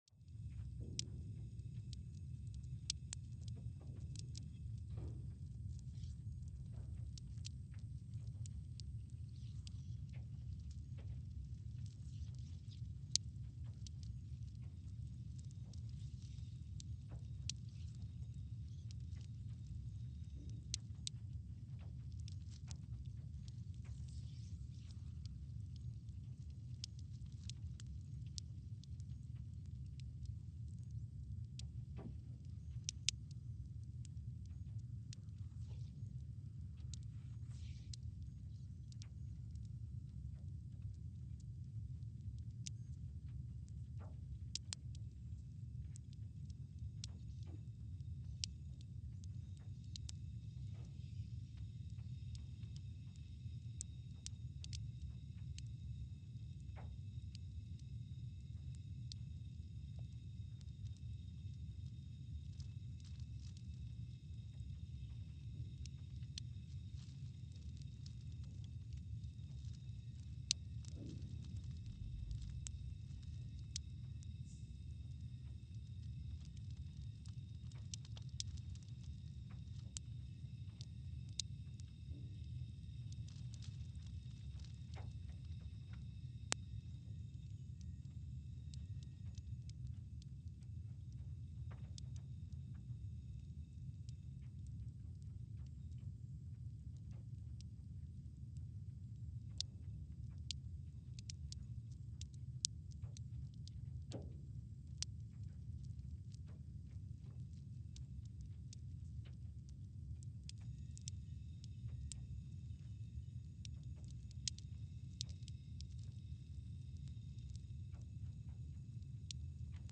Scott Base, Antarctica (seismic) archived on June 25, 2022
Sensor : CMG3-T
Speedup : ×500 (transposed up about 9 octaves)
Loop duration (audio) : 05:45 (stereo)
SoX post-processing : highpass -2 90 highpass -2 90